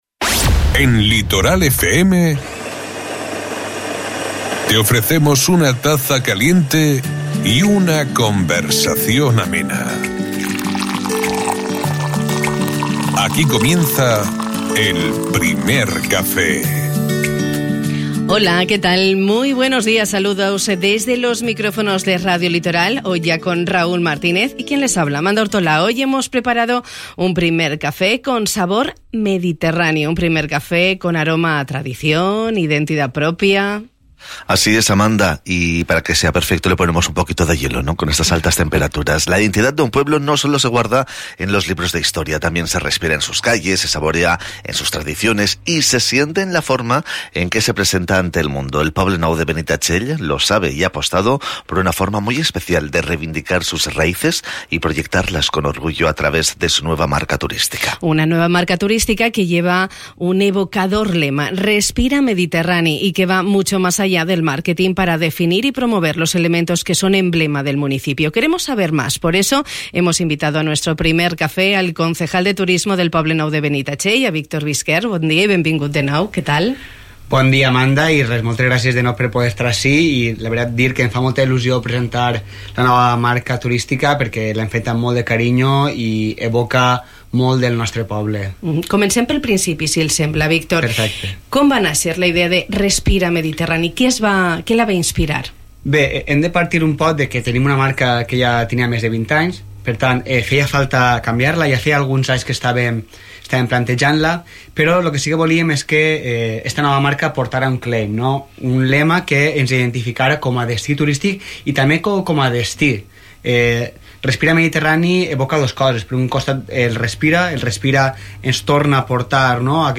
Ens ho ha explicat el regidor de Turisme del Poble Nou de Benitatxell, Víctor Bisquert.